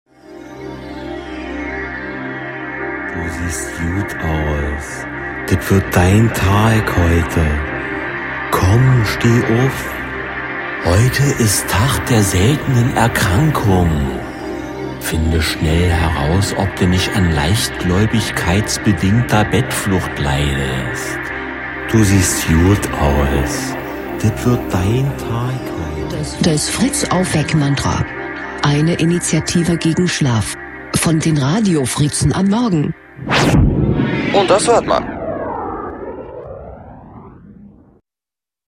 FritzAufweck-Mantra 28.02.17 (Erkrankung) | Fritz Sound Meme Jingle